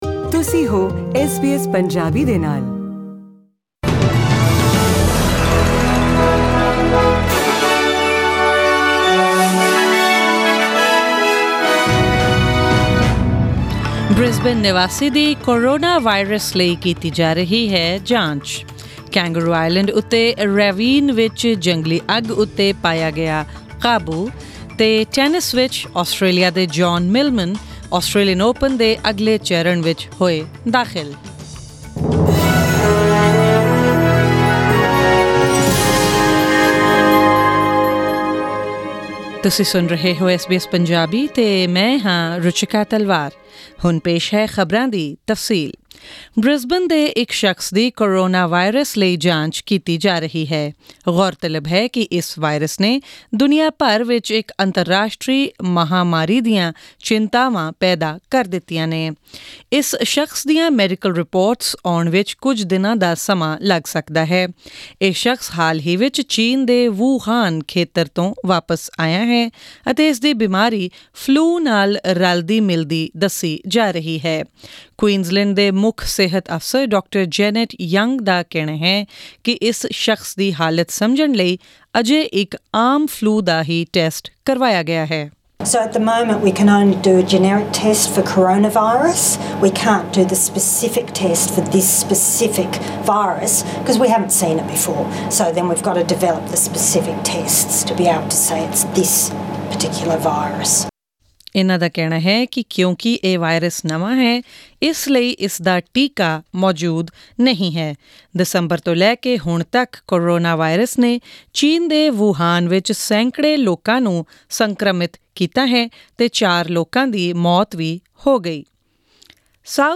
Presenting the major news stories of today with updates on sports, currency exchange rates and the weather forecast for tomorrow.